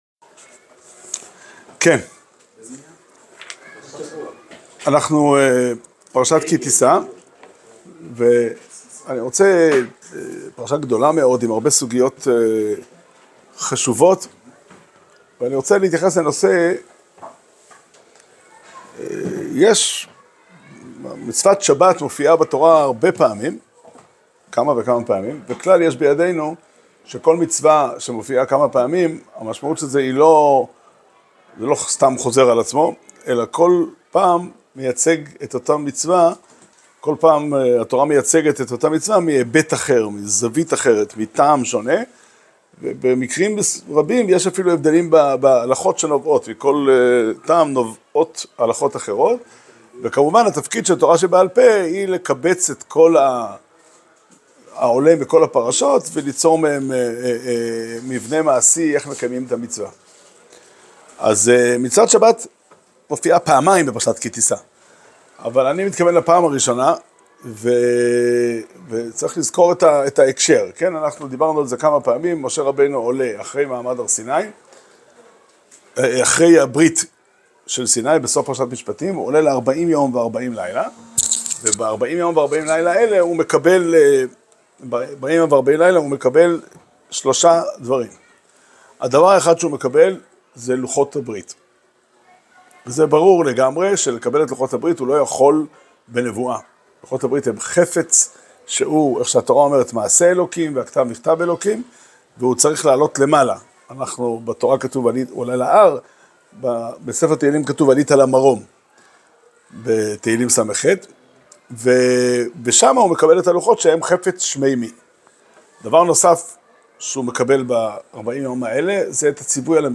שיעור שנמסר בבית המדרש פתחי עולם בתאריך י' אדר תשפ"ה